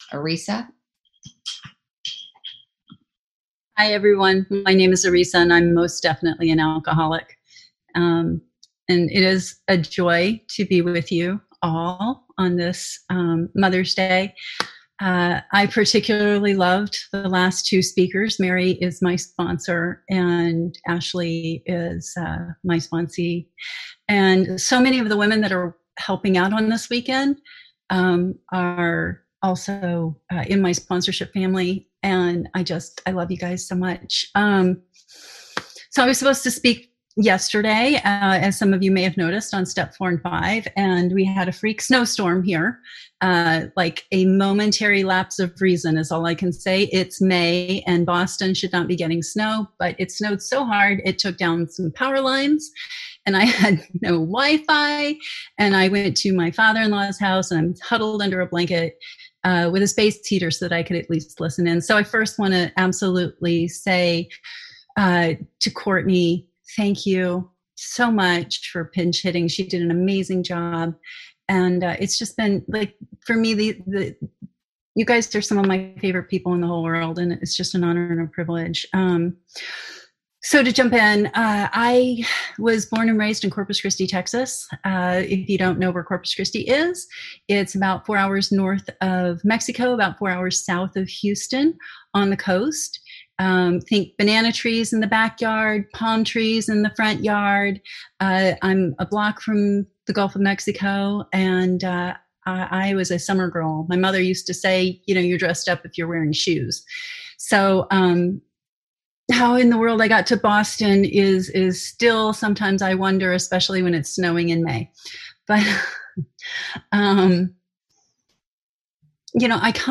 Womens Wisdom AA Weekend